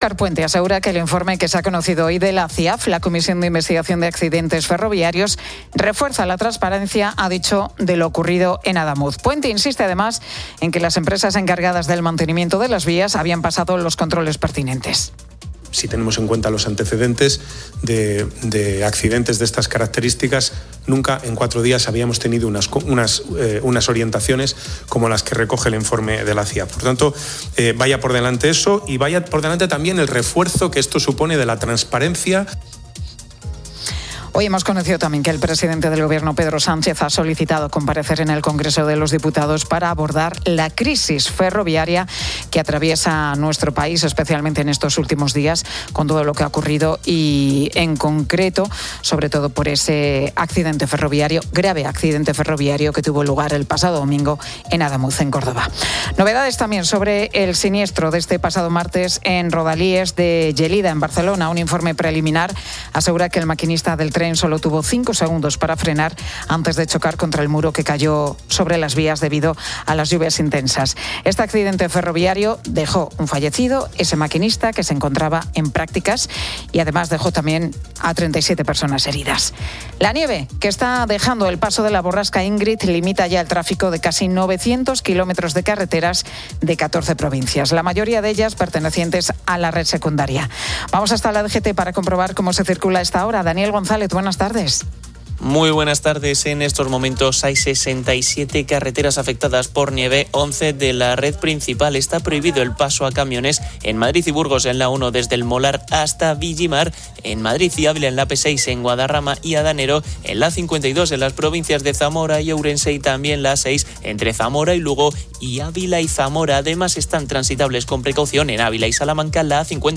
Oyentes comparten destinos de viaje (Egipto, Japón) y recomiendan Portugal.